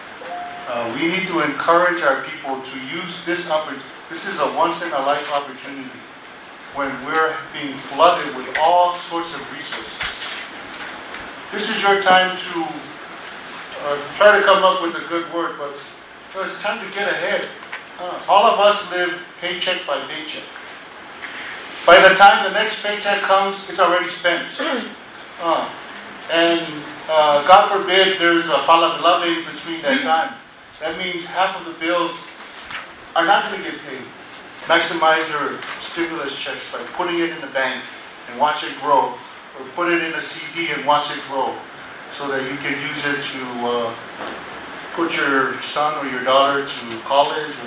At last week’s cabinet meeting Lt Governor Talauega sounded a word of caution and asked directors to counsel employees, families and friends to use their stimulus windfalls to get ahead.